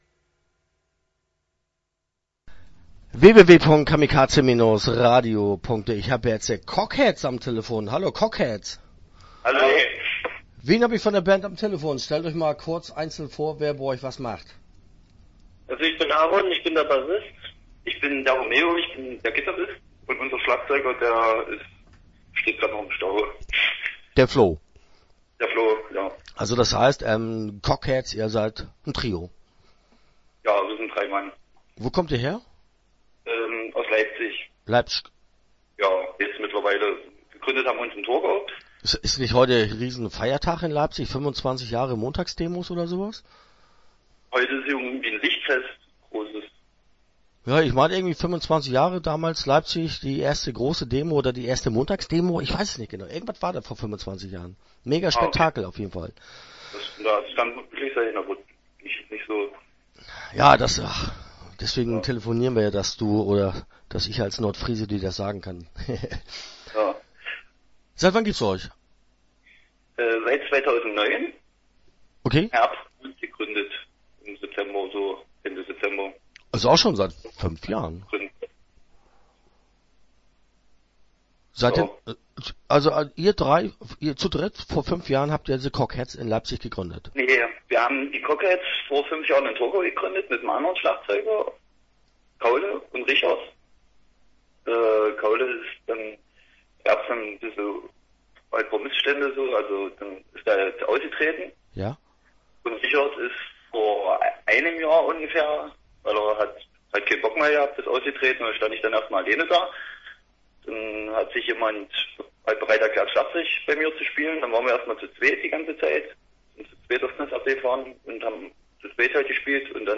Start » Interviews » The Cockheads